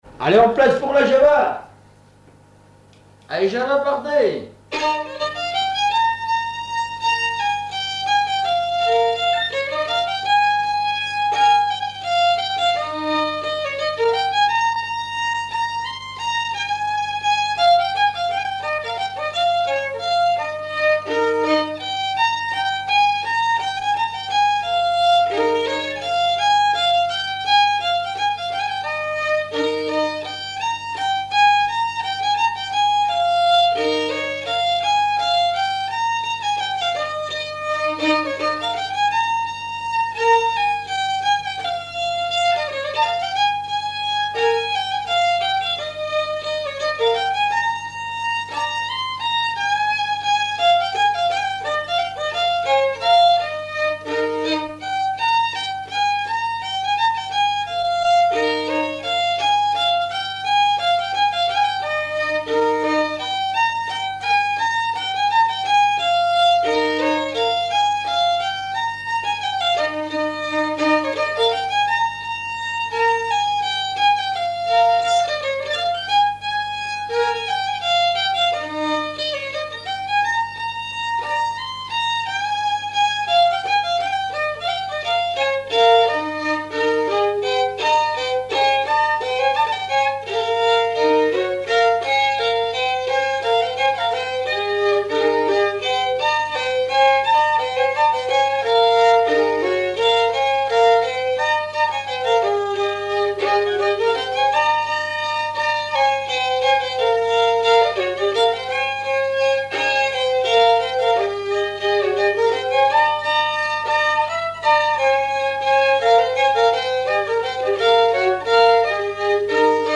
Saint-Vincent-Sterlange
danse : java
Auto-enregistrement
Pièce musicale inédite